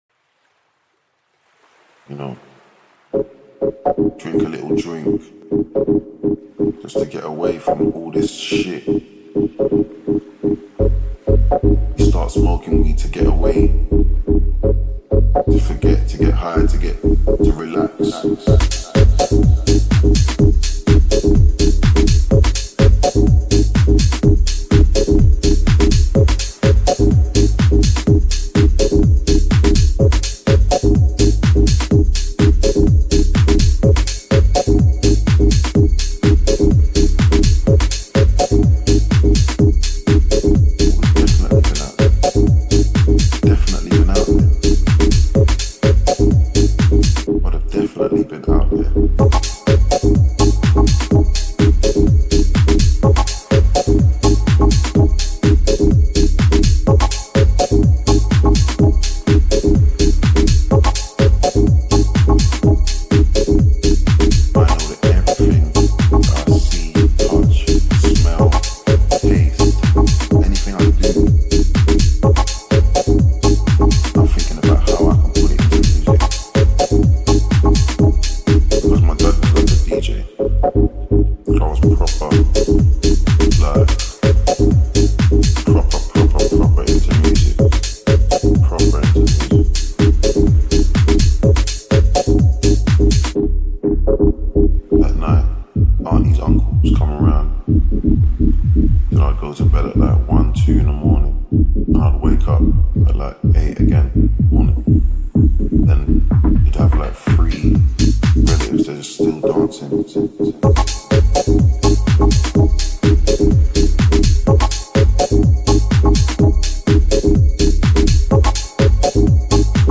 intromusic.mp3